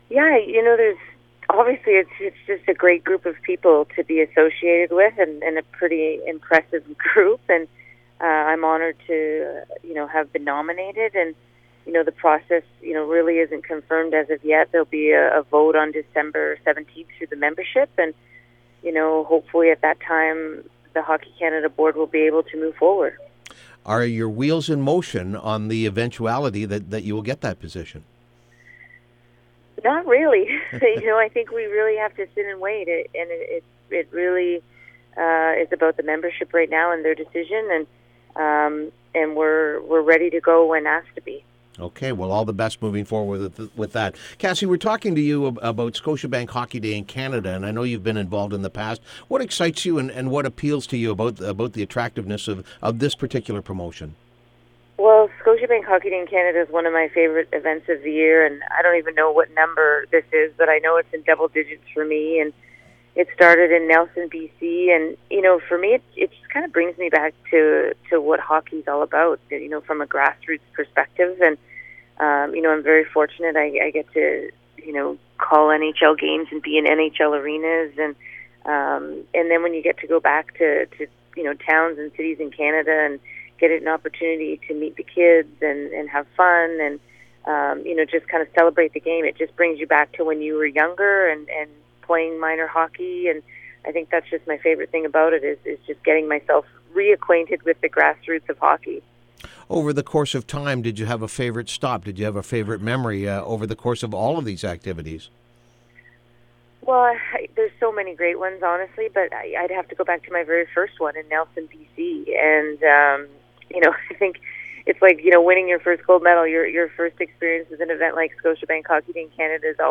Scotiabank Hockey Day In Canada Preview. Interview with Cassie Campbell Pascall